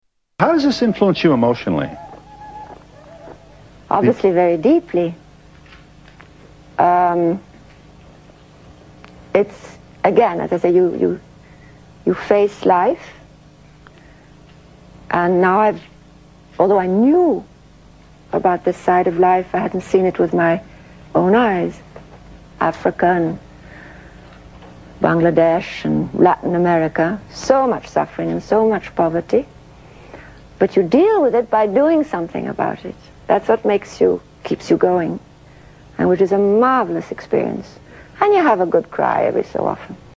Tags: Audrey Hepburn clips Audrey Hepburn interview Audrey Hepburn audio Audrey Hepburn Actress